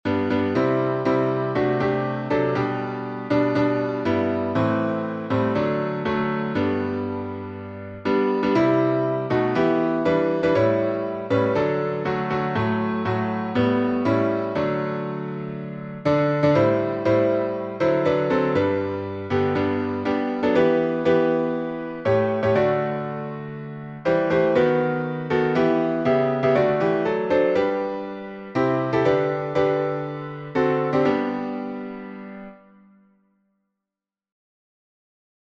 The Birthday of a King — D Major.